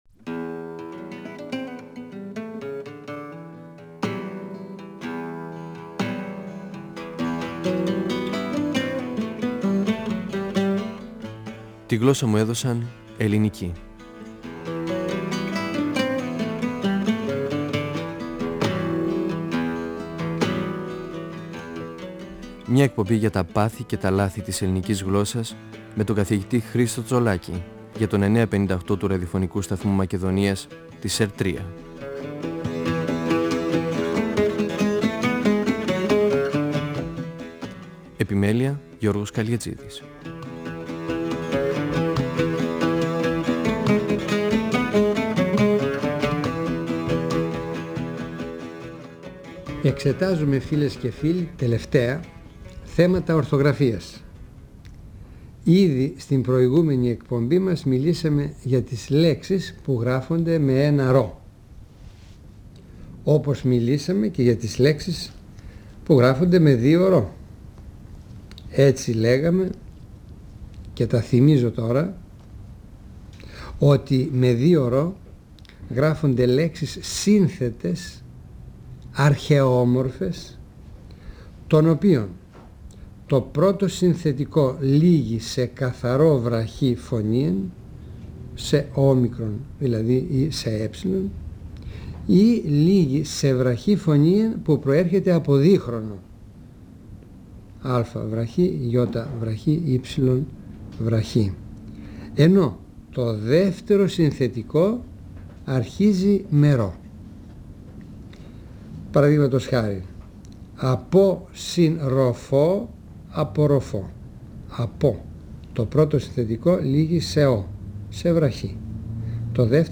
Ο γλωσσολόγος Χρίστος Τσολάκης (1935-2012) μιλά για την ορθογραφία των λέξεων με μ. Τι συμβαίνει όταν το θέμα της λέξης λήγει σε π, β, φ; Τι συμβαίνει για τα ψευδοχειλικά ευ, αυ; Τι στις σύνθετες λέξεις και ποιες είναι εκτός του κανόνα;
ΦΩΝΕΣ ΑΡΧΕΙΟΥ του 958fm της ΕΡΤ3 958FM Αρχειο Φωνες Τη γλωσσα μου εδωσαν ελληνικη "Φωνές" από το Ραδιοφωνικό Αρχείο Εκπομπές